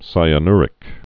(sīə-nrĭk, -nyr-)